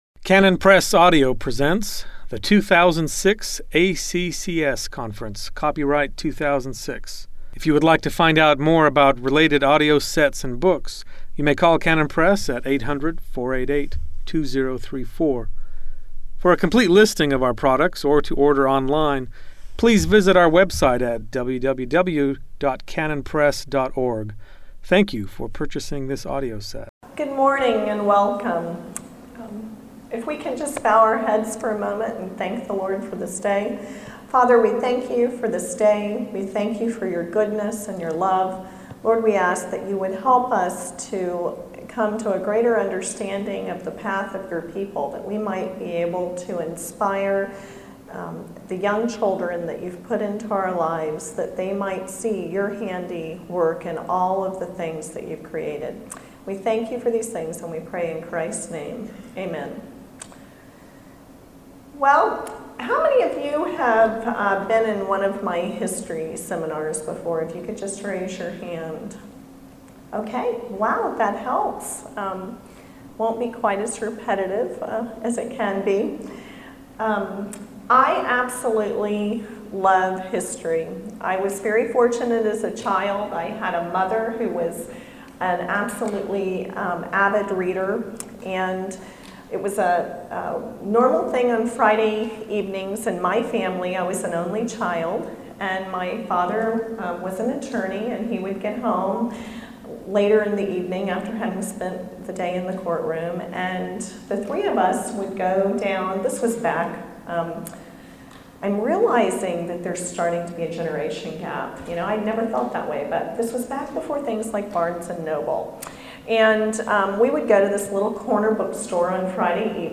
2006 Workshop Talk | 0:30:50 | K-6, History
Learn why it makes sense to teach children history chronologically and what the best methods are for doing this. Speaker Additional Materials The Association of Classical & Christian Schools presents Repairing the Ruins, the ACCS annual conference, copyright ACCS.